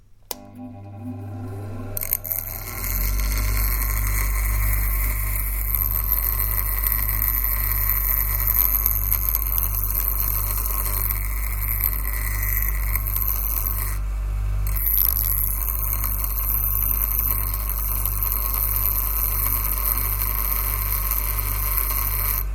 Sander in use
Duration - 22s Environment -The space is a indoors in a large shed with a corrugated roof. it has a slight opening on one side of the shed which is attached to another shed. Description - The sander is mounted onto a wooden counter.
When turned one, a small metal bar was used to create this sound. Sparks were flying out of the device in all angles when both materials were impacted together.